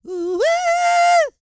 E-CROON 3025.wav